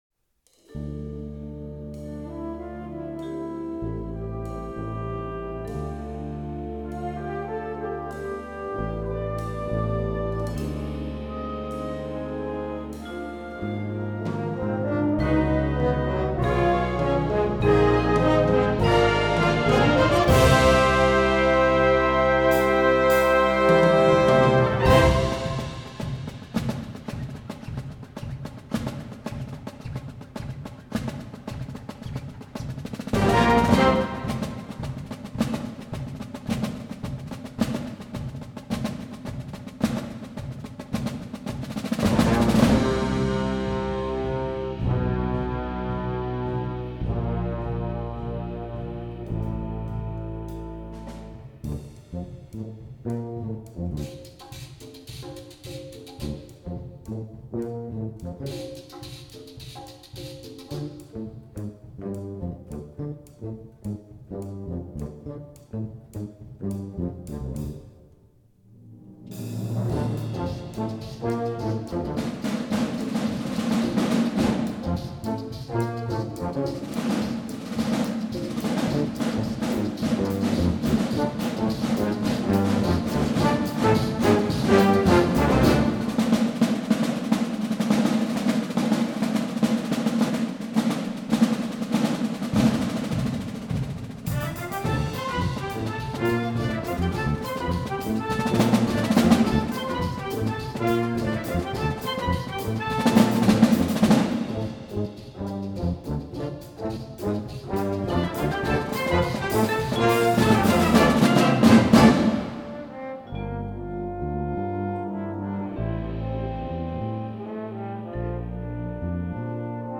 Besetzung: Drum Corps & Brass Band